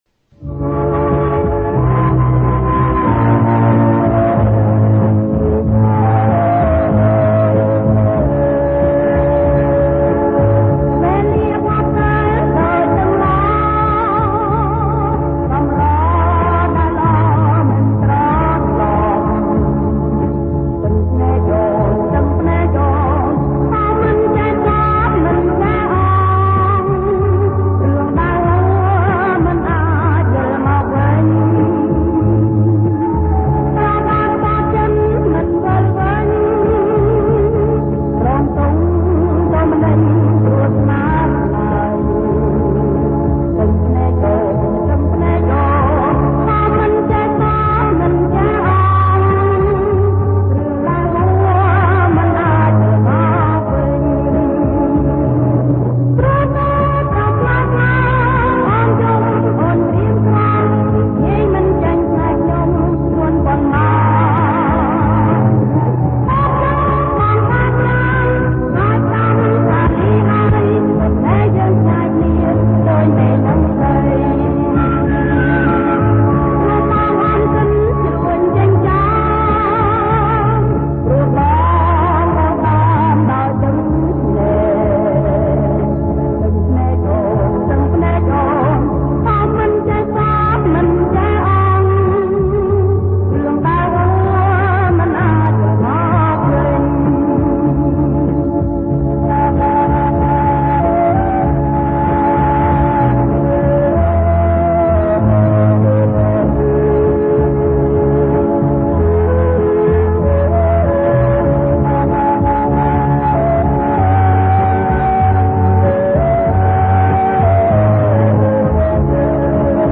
ប្រគំជាចង្វាក់ Jerk Lent